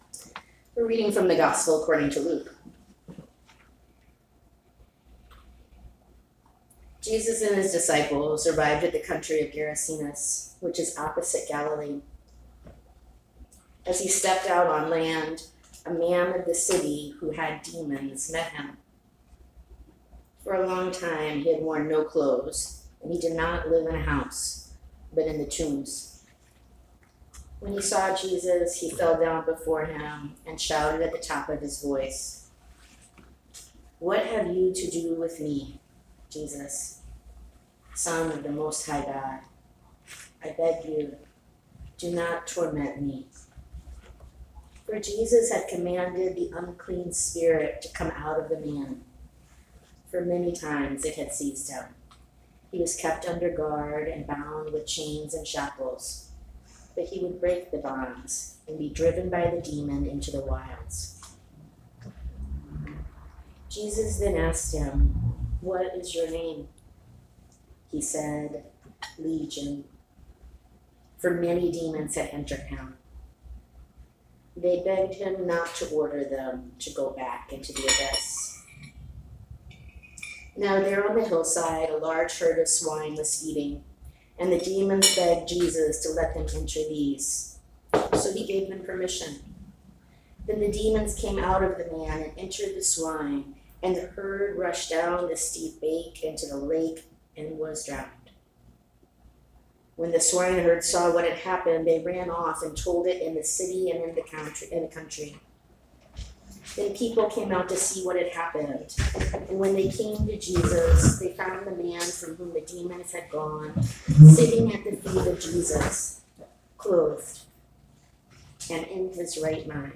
St. Lydia's: A Dinner Church in Brooklyn, NY > Blog
June 24, 2019 Sermon